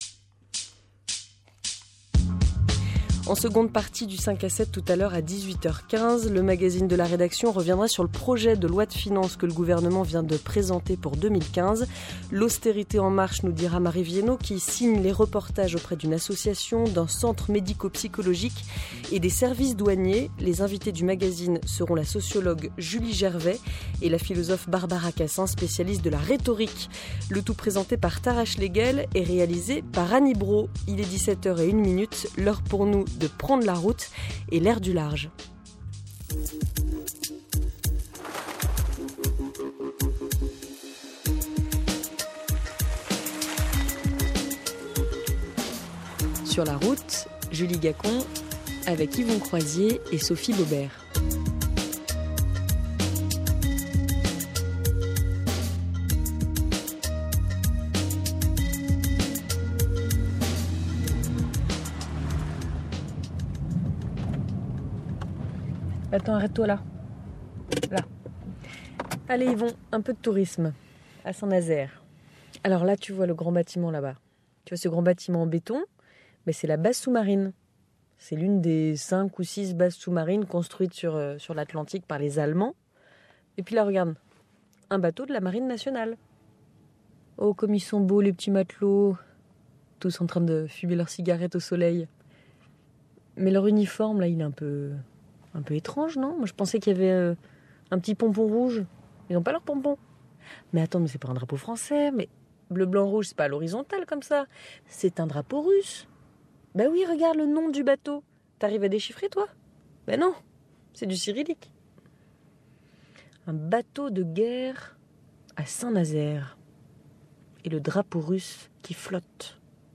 Entretien de No Mistrals for Putin sur France Culture